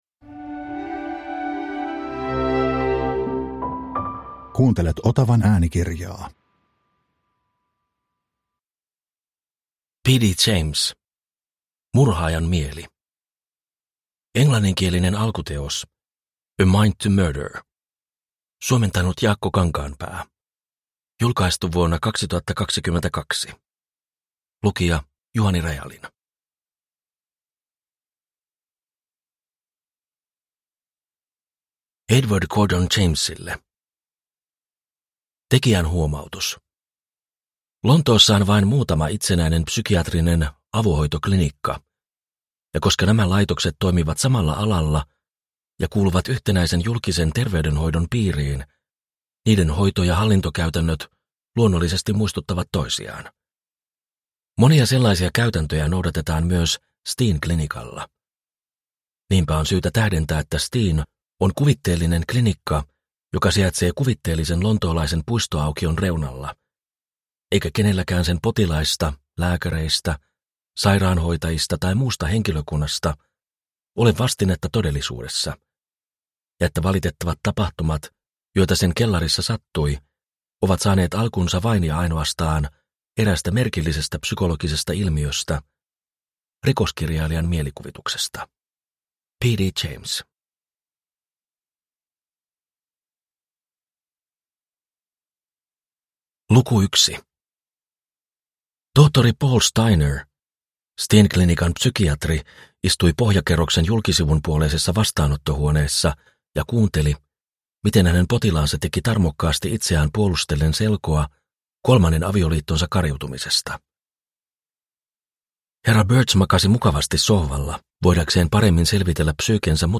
Murhaajan mieli – Ljudbok – Laddas ner